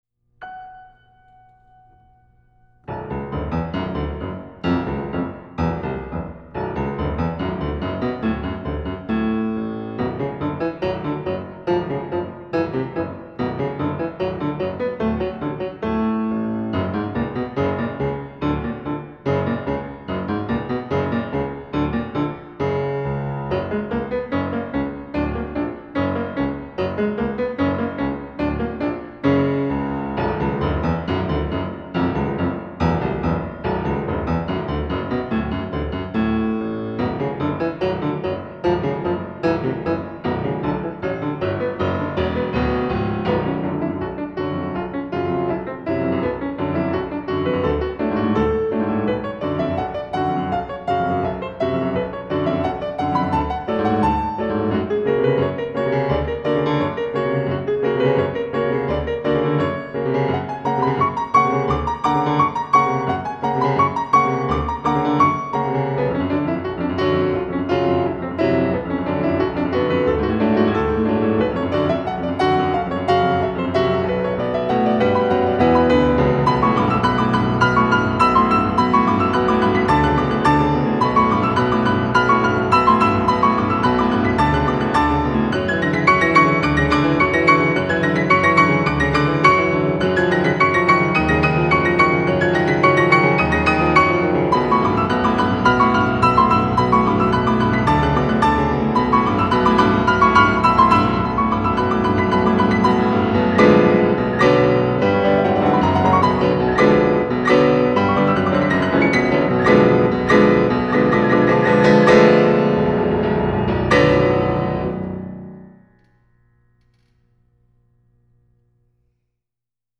歌曲风格：轻音乐 (Easy Listening) / 纯音乐 (Pure Music)
以钢琴演奏四部剧目 胡桃钳、皮尔金、天使组曲、崔斯坦与伊索德